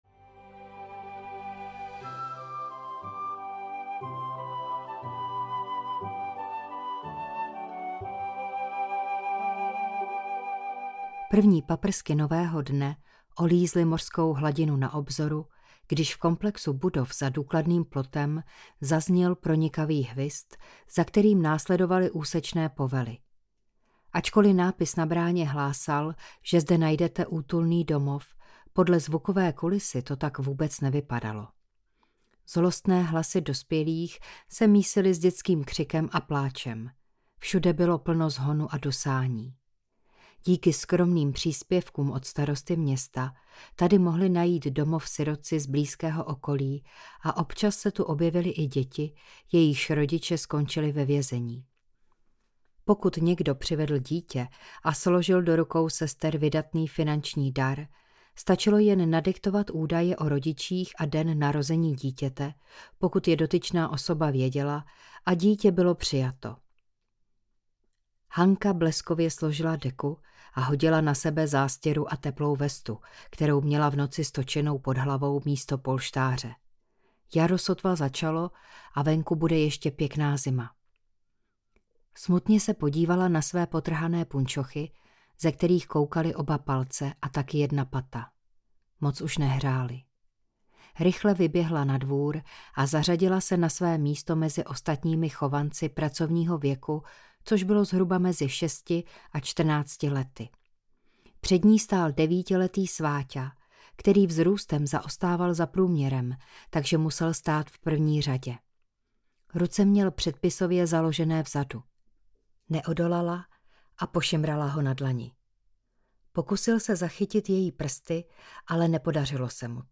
Ostrov zasvěcení audiokniha
Ukázka z knihy